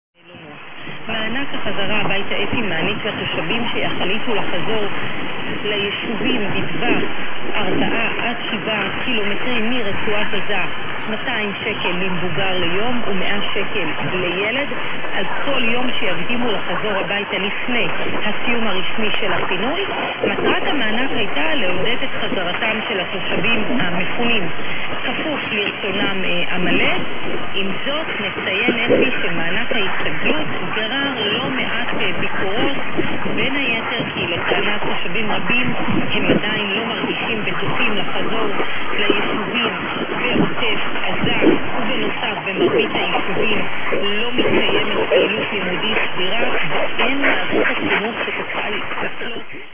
with a woman in Hebrew and minutes later, with